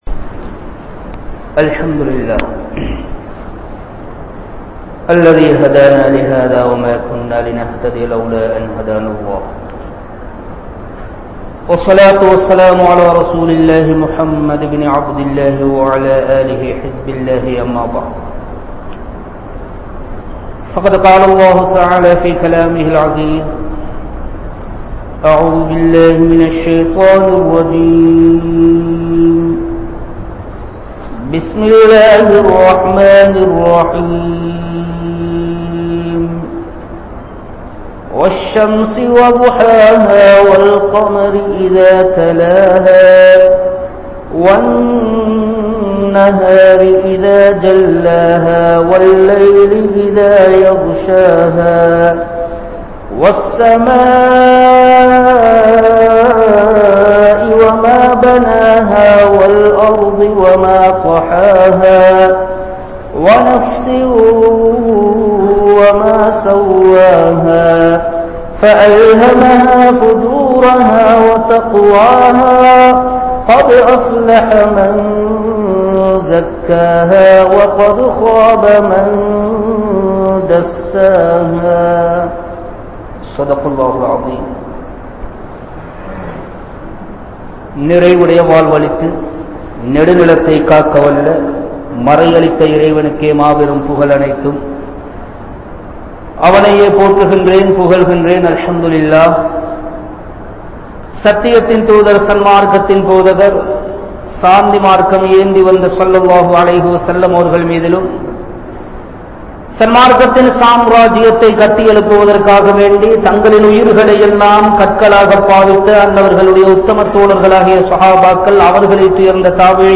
Manithanin Theeya Aasaihal (மனிதனின் தீய ஆசைகள்) | Audio Bayans | All Ceylon Muslim Youth Community | Addalaichenai